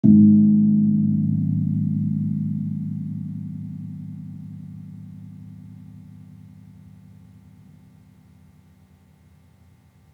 Gong
Gamelan Sound Bank
Gong-G#2-p.wav